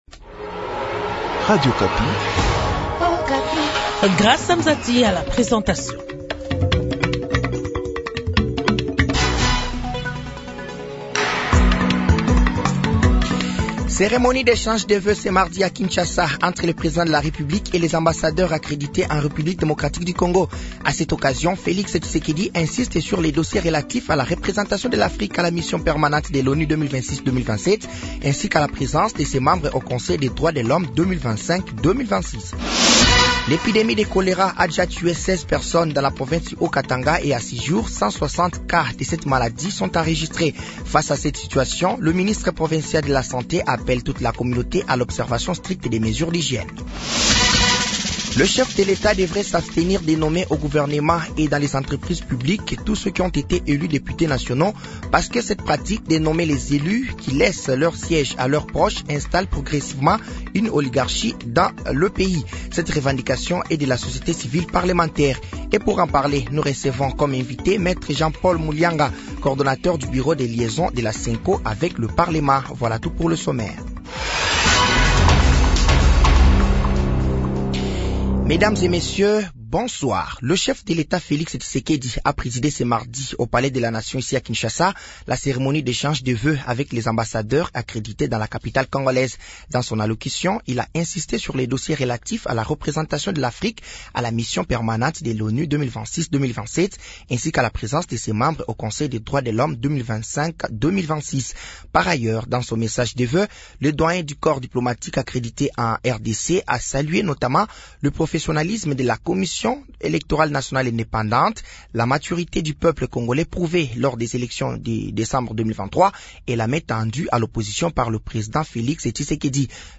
Journal français de 18h de ce mardi 30 janvier 2024